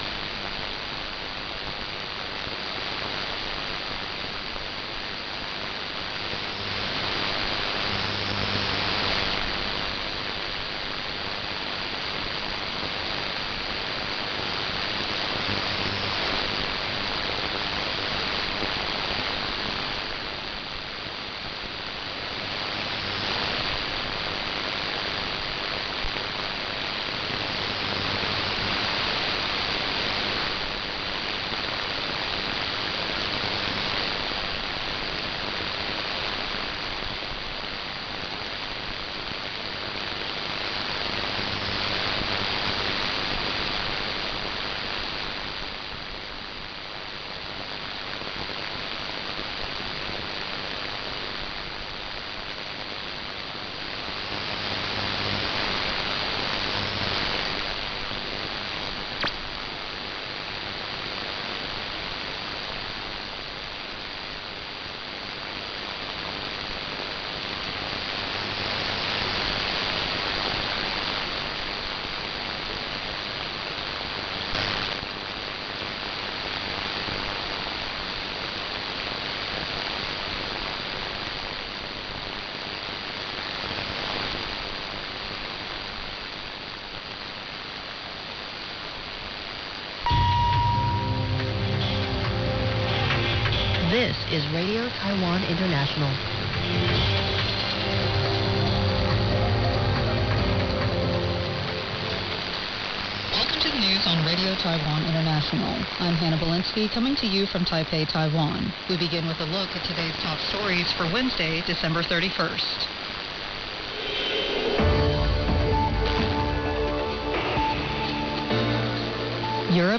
I made this recording of their interval signal and sign-on in early 1971.